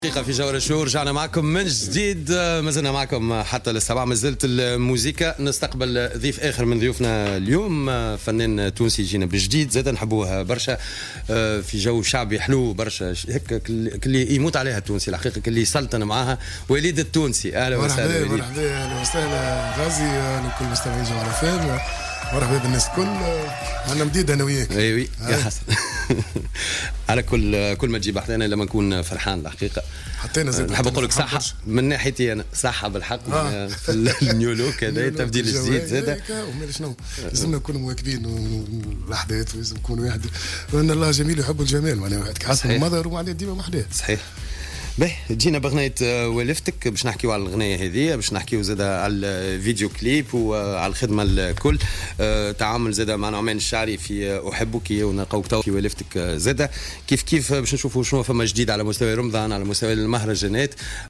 نزل الفنان وليد التونسي مساء اليوم السبت ضيفا على "الجوهرة اف أم" في برنامج "جوهرة شوو" تحدث خلاله عن برامجه خلال شهر رمضان وفي فترة المهرجانات.